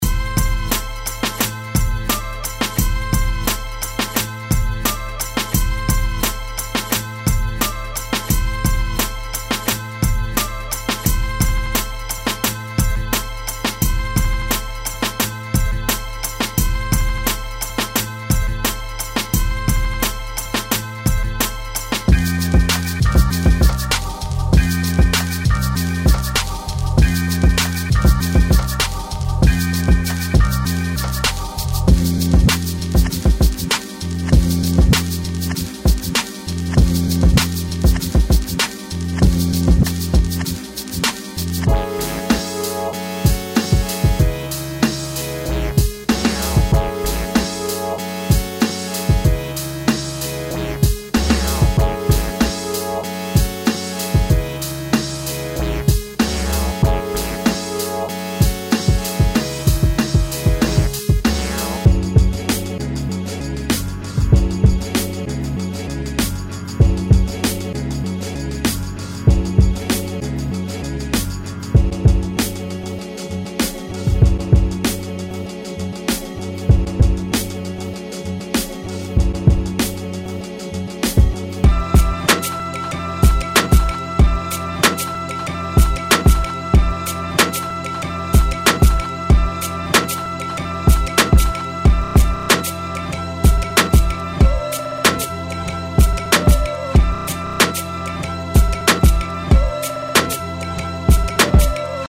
لوپ و سمپل هیپ هاپ 90s Rap King | دانلود سمپل رایگان | لوپ و سمپل رایگان